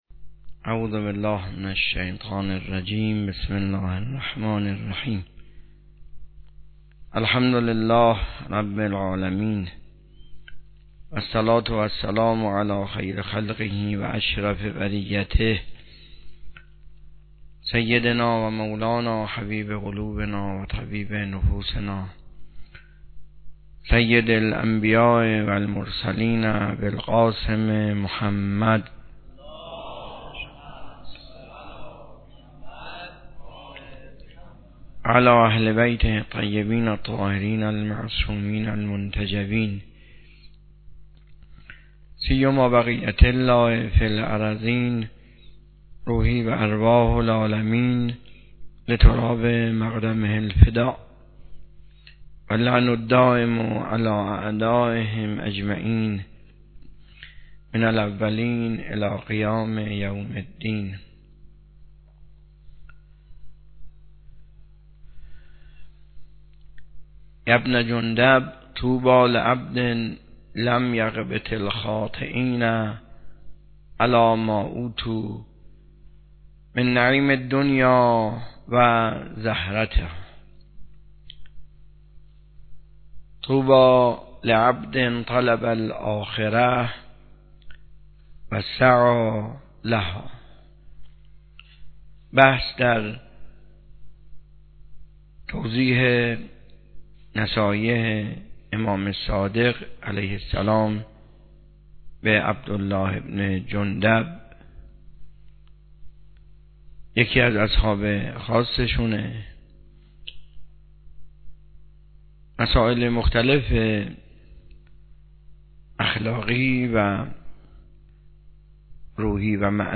سخنرانی
حوزه علمیه معیر تهران